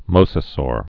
(mōsə-sôr)